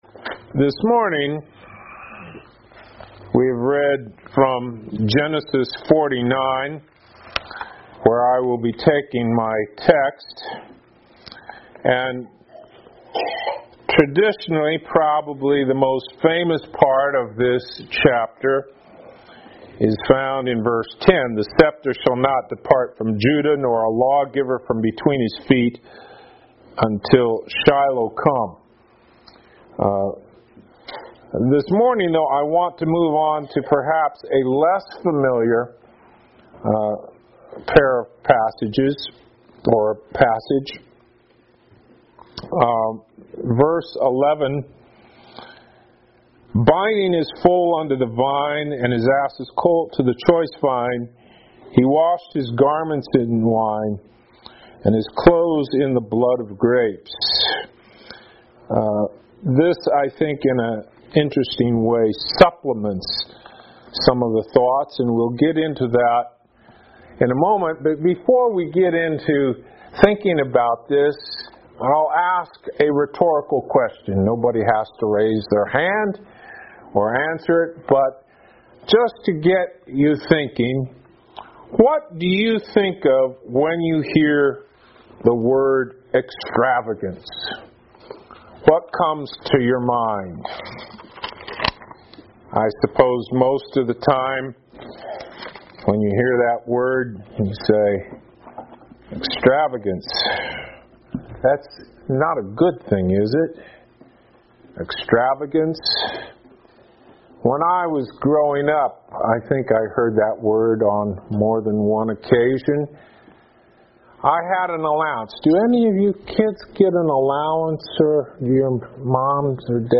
Morning Worship Passage: Genesis 49:9-12 Service Type: Sunday Morning %todo_render% « “A Healing Church” Part 2 “Jesus